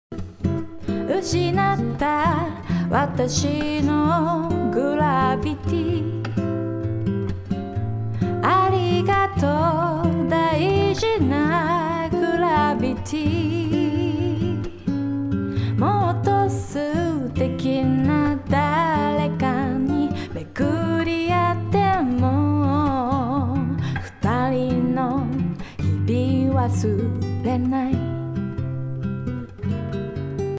一方のダイナミックEQで作った音の場合。
コンプレッションによって抑えたのかEQによって抑えたのかによって音のニュアンスが違いましたね。